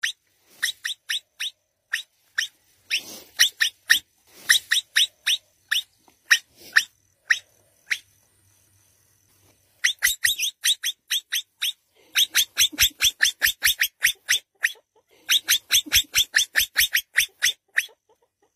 Tiếng Capybara kêu
Tiếng Chuột lang kêu Âm thanh Run Rẩy… (Hoạt hình)
Thể loại: Tiếng động vật hoang dã
tieng-capybara-keu-www_tiengdong_com.mp3